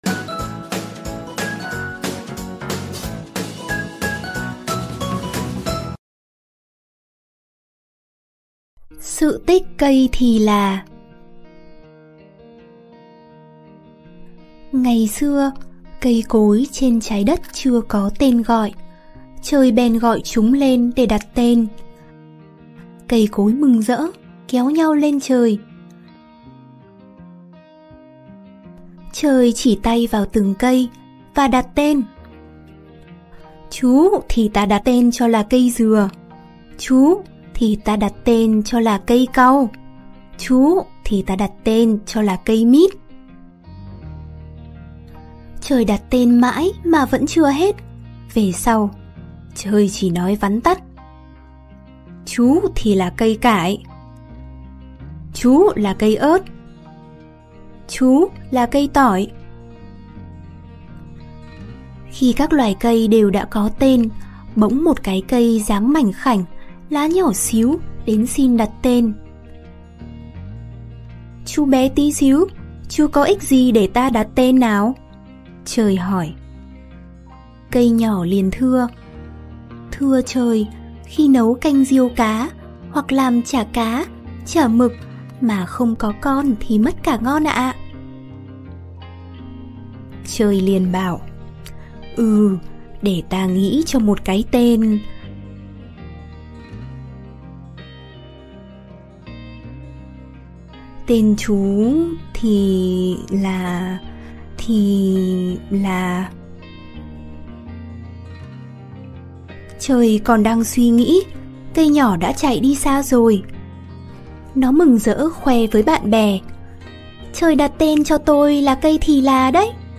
Sách nói | Sự tích cây thì là